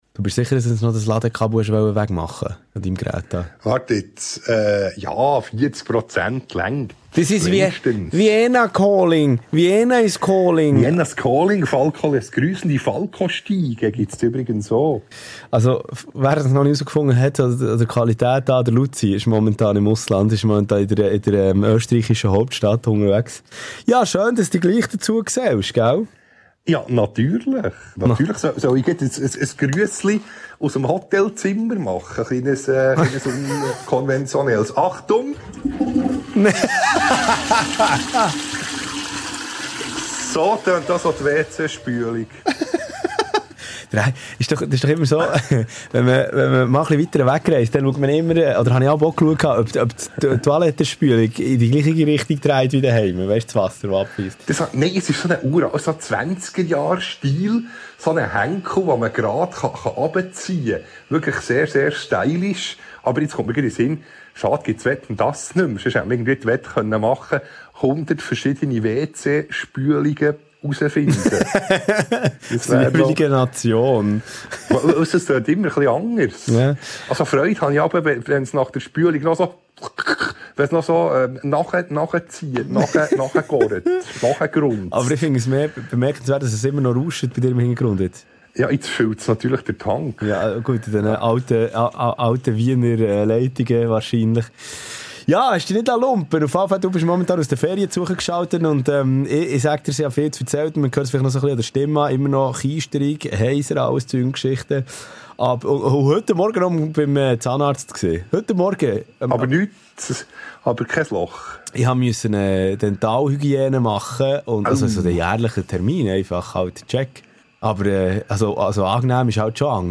Das exklusive Interview!